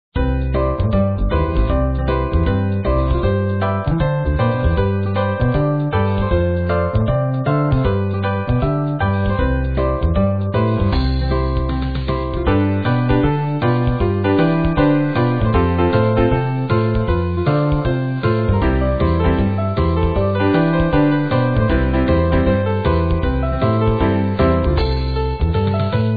Country
Une musique country pouvant se lire en boucle.